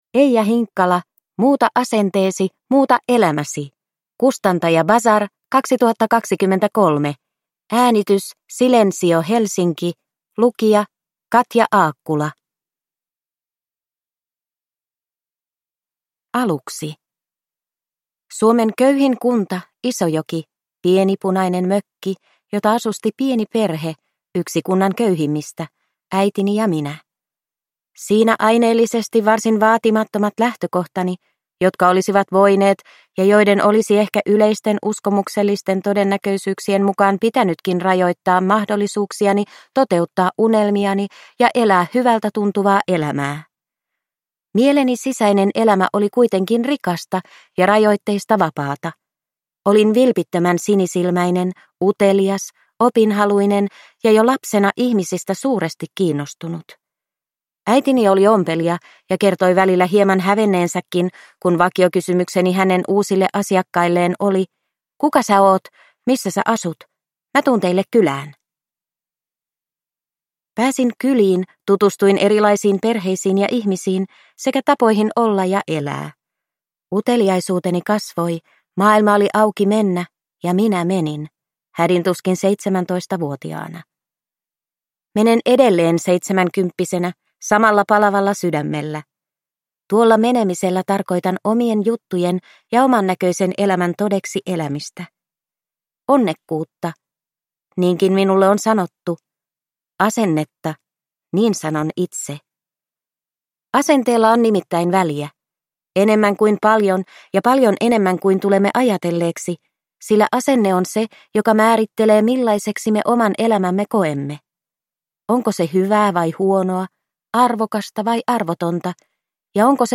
Muuta asenteesi, muuta elämäsi – Ljudbok – Laddas ner